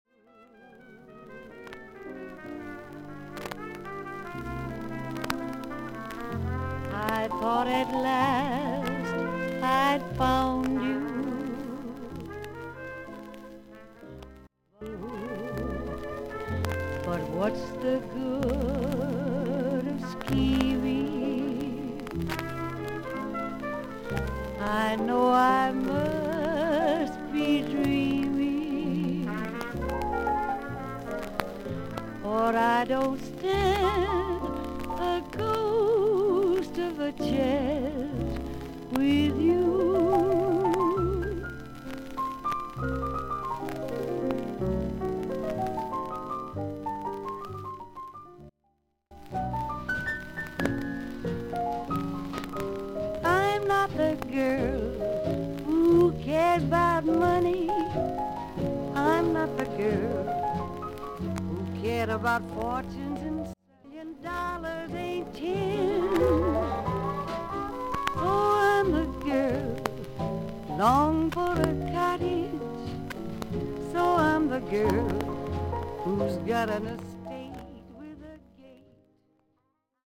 所々にパチノイズの箇所あり。全体的にサーフィス・ノイズあり。試聴はノイズが多めの箇所です。
30年代から活躍する麗しい女性シンガー。